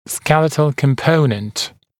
[‘skelɪtl kəm’pəunənt][‘скелитл кэм’поунэнт]гнатическая составляющая, скелетный компонент